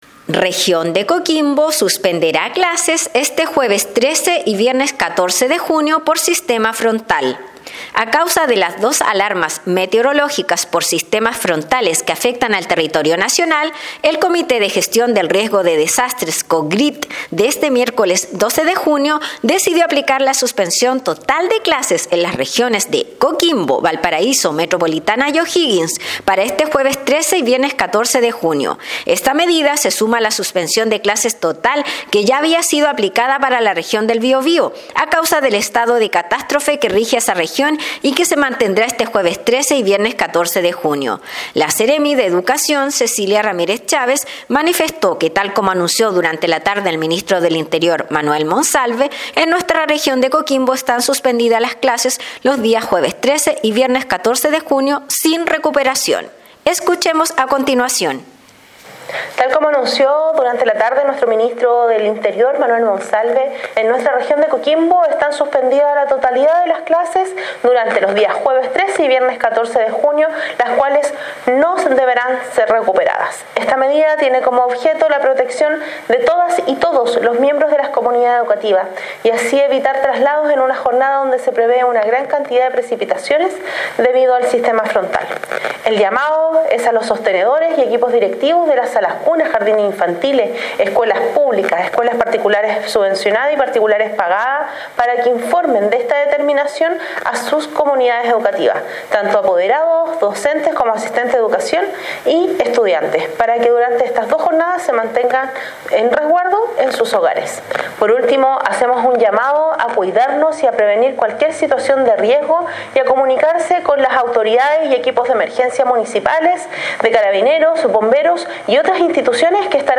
Despacho-Radial-Region-de-Coquimbo-suspendera-clases-este-jueves-13-y-viernes-14-de-junio-por-sistema-frontal_.mp3